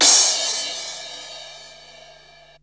CRASH03   -L.wav